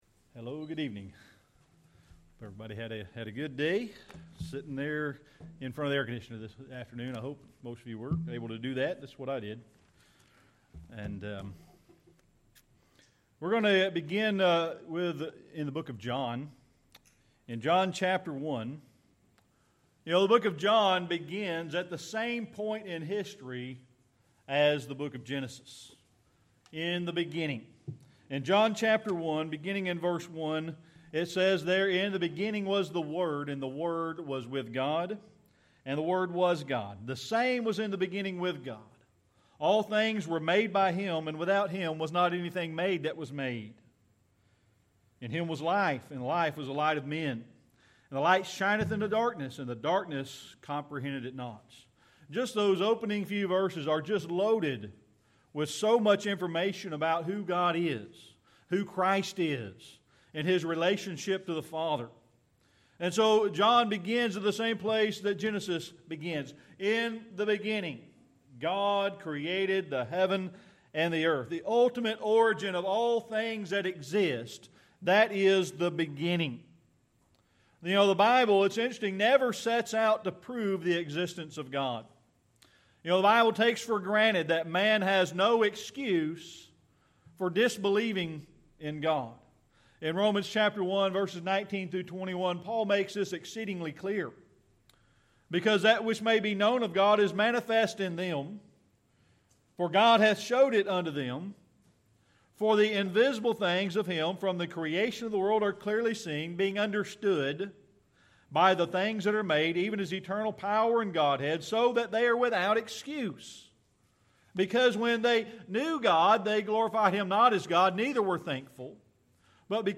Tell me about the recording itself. John 1:1-3 Service Type: Sunday Evening Worship John 1:1-18 The book of John begins as the same point in history as Genesis.